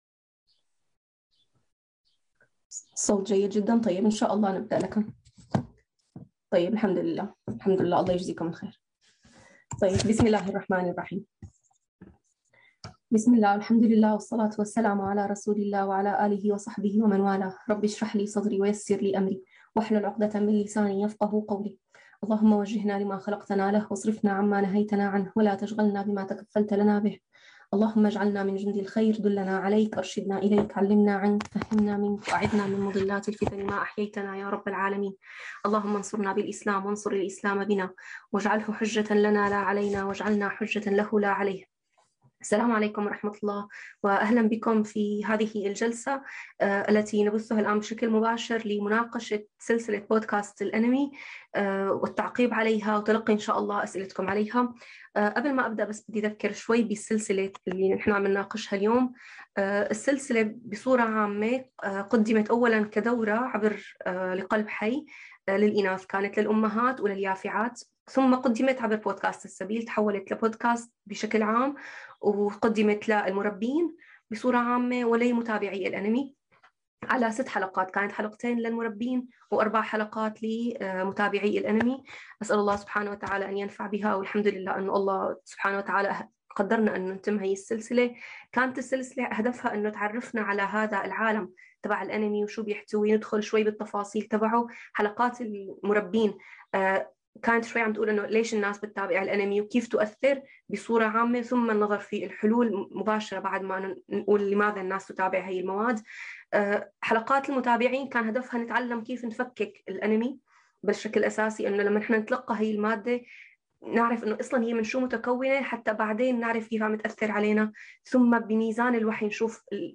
جلسة تم بثها على صفحة السبيل على فيسبوك لمناقشة سلسلة بودكاست “هل الأنمي كرتون بريء؟” والتي قدمت بحلقاتها الستة على منصة السبيل.
anime-discussion.mp3